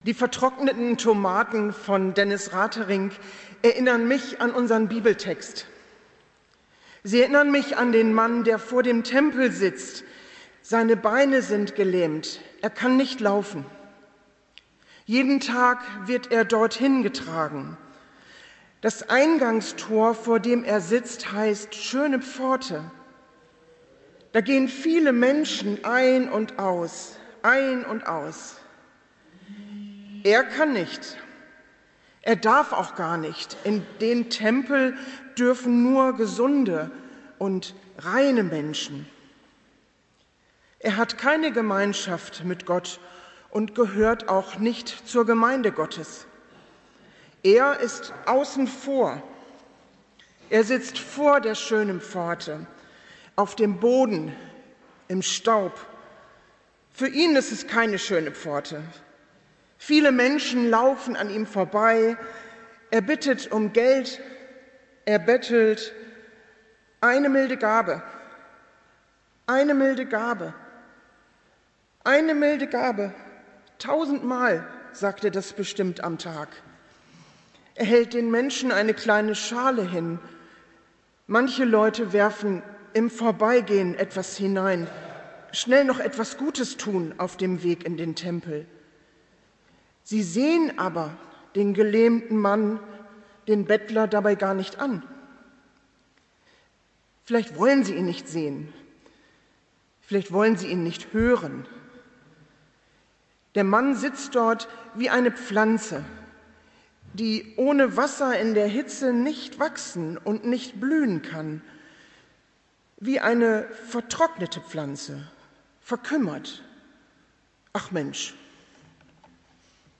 Predigt des Gottesdienstes in einfacher Sprache unter dem Motto, „Einfach aufblühen“, aus der Eckardtskirche vom 7. September 2025